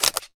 Shutter.ogg